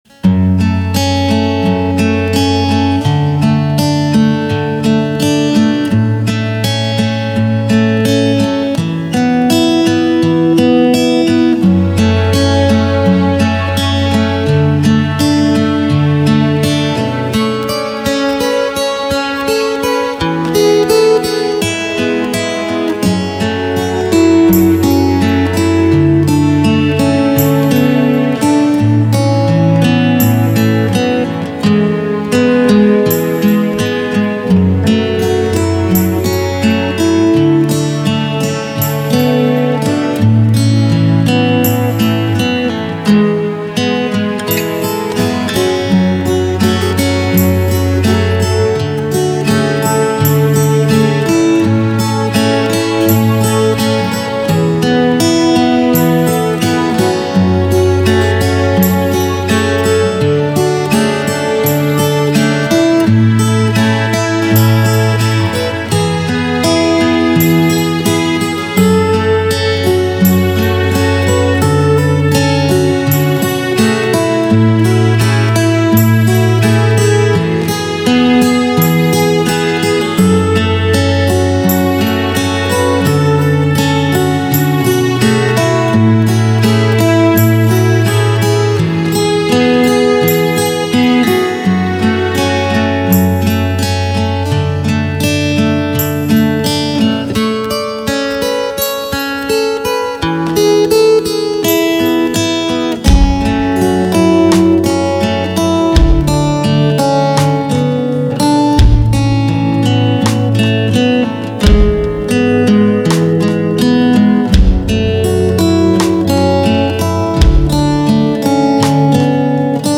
Клавиши еще ладно, фиг с ними, а вот бубен, че там бочка или что во втором куплете, всё мимо кассы.....Ну тут наверное претензия не к самим ударным, а к тому что гитара кривая, соответственно и барабан с бубном так добавились Вложения тест клавиши + ударные.mp3 тест клавиши + ударные.mp3 4,6 MB · Просмотры: 924